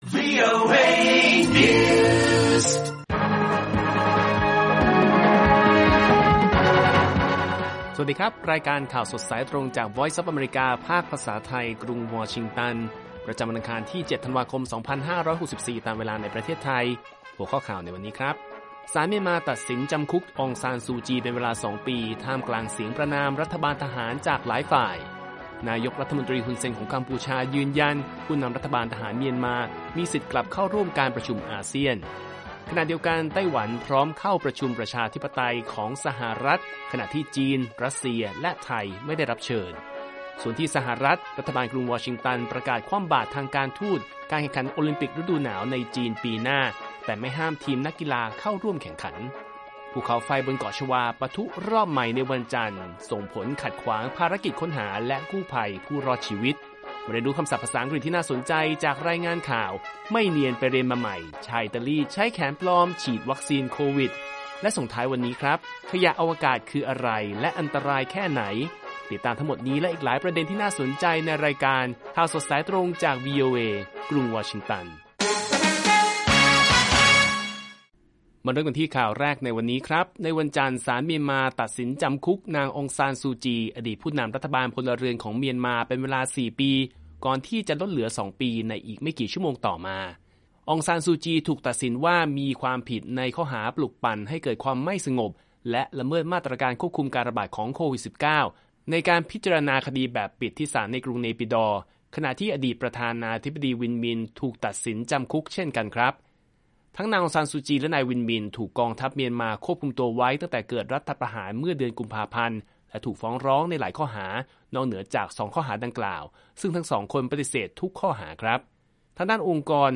ข่าวสดสายตรงจากวีโอเอ ภาคภาษาไทย ประจำวันอังคารที่ 7 ธันวาคม 2564 ตามเวลาประเทศไทย